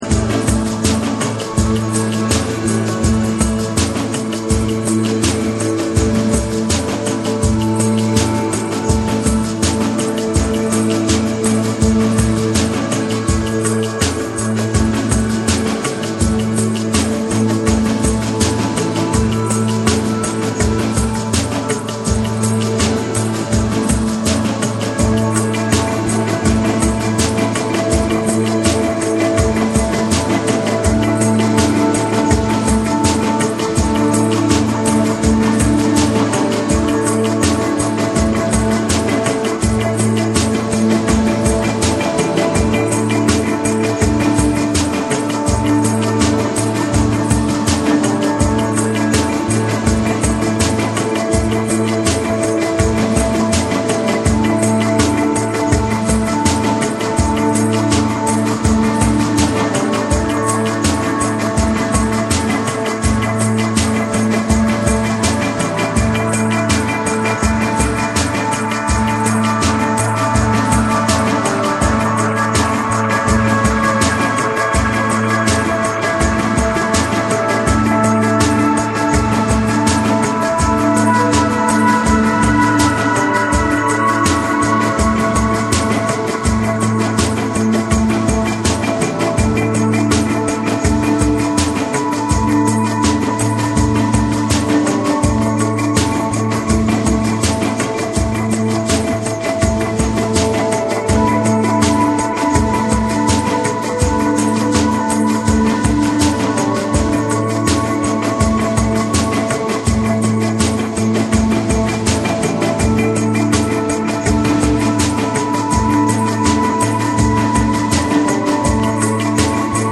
生演奏の質感とエレクトロニックなプロダクションが自然に溶け合った、柔らかく心地よいバレアリック・サウンドを展開。
TECHNO & HOUSE